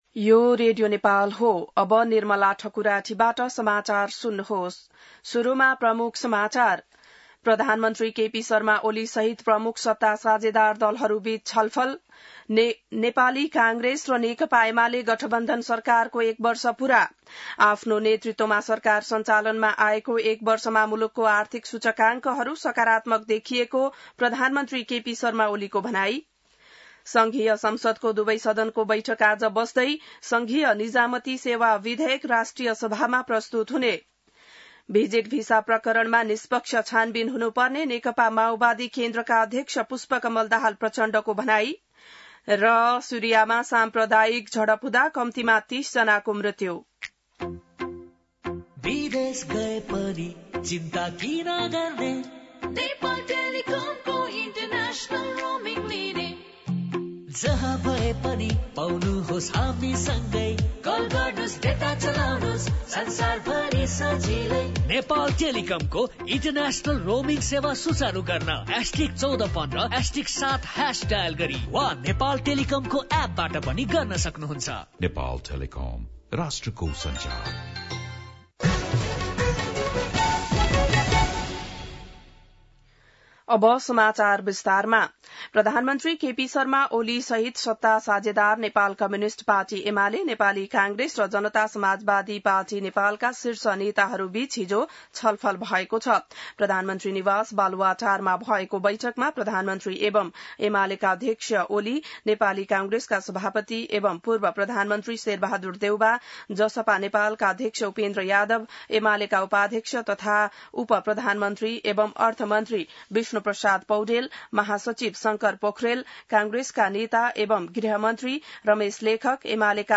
बिहान ७ बजेको नेपाली समाचार : ३१ असार , २०८२